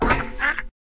duck3.ogg